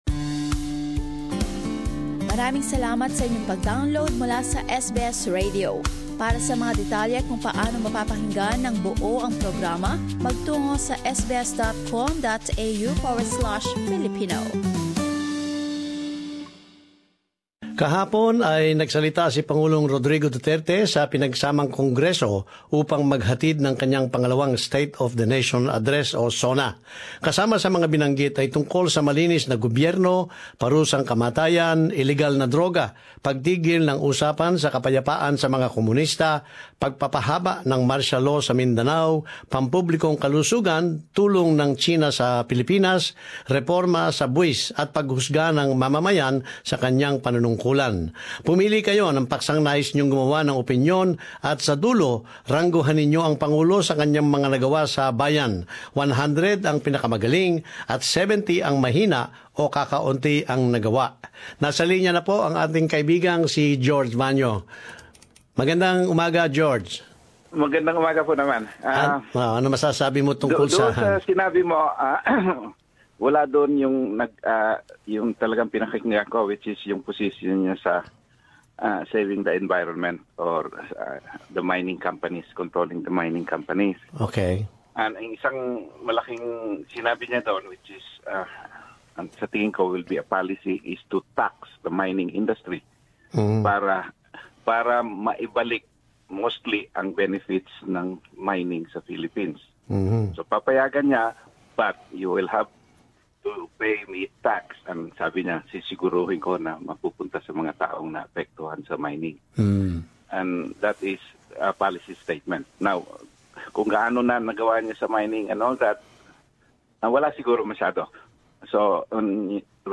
I defer to the peoples judgment, ito ang sinabi ni Pangulong Rodrigo Duterte sa ikalawa niyang State of the Nation Address (SONA).Larawan: Pangulo ng Pilipinas na si Rodrigo Duterte (SBS) Sa talkback ngayong Linggo, tinanong namin ang opinyon ng mga Pilipino-Australyano sa naging ulat na pagbabago ni Pangulong Rodrigo Duterte ganoon na rin ang kanilang marka sa kanya bilang pinuno ng bansang Pilipinas.